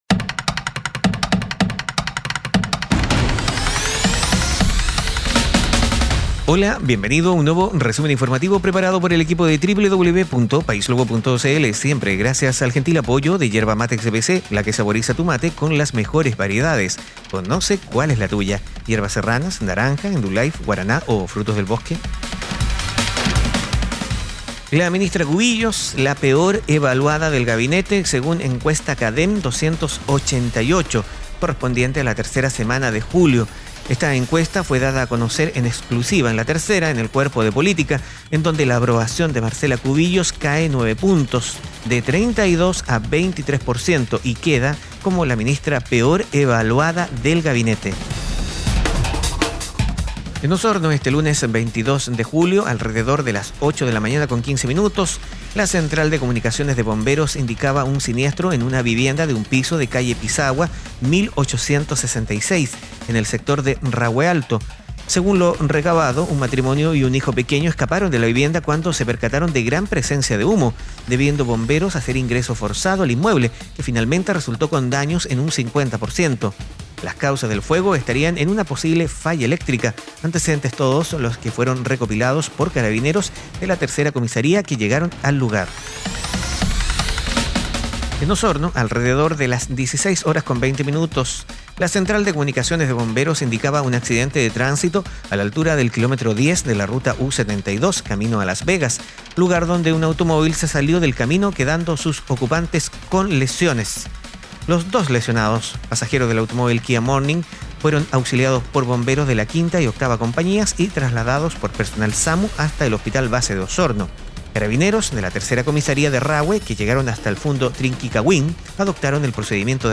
Resumen Informativo - Lunes 22 de Julio de 2019